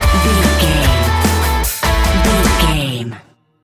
Epic / Action
Ionian/Major
Fast
drums
electric guitar
bass guitar